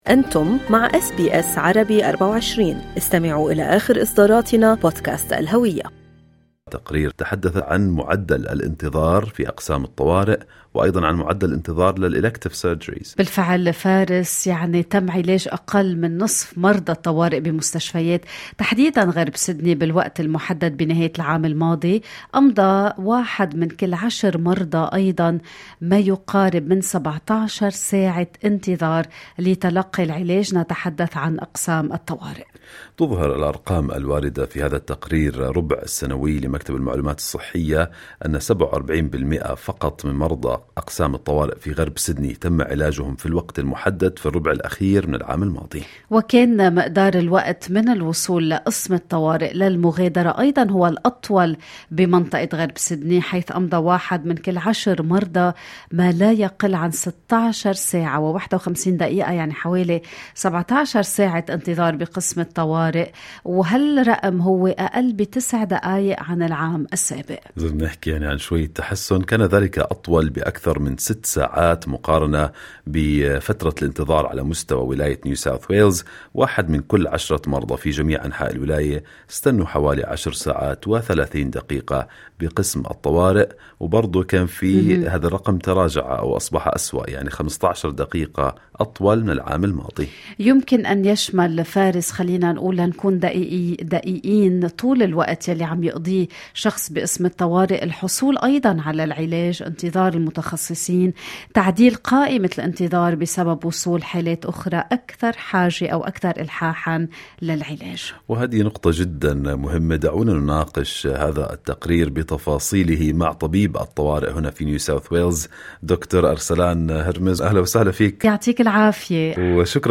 "انتظار لـ17 ساعة": طبيب طوارئ يشرح أسباب التأخير في تقديم الخدمة في نيو ساوث ويلز